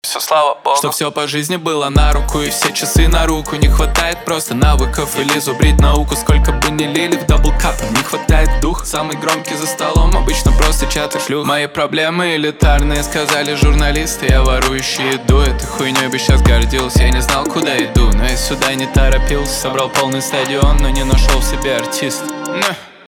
русский рэп , битовые , басы , качающие